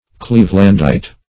Search Result for " cleavelandite" : The Collaborative International Dictionary of English v.0.48: Cleavelandite \Cleave"land*ite\, n. [From Professor Parker Cleaveland.]
cleavelandite.mp3